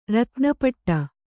♪ ratna paṭṭa